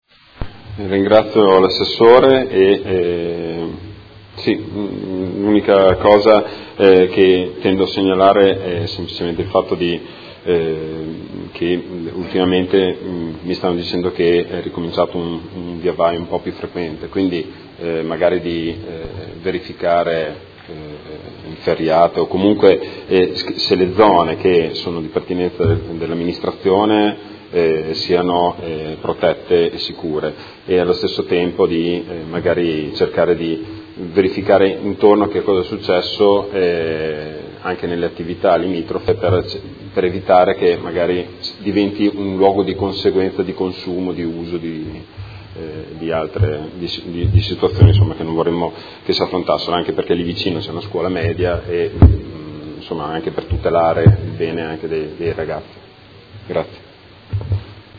Seduta del 24/01/2019. Conclude interrogazione del Gruppo Consiliare Movimento cinque Stelle avente per oggetto: Area ex Mercato Bestiame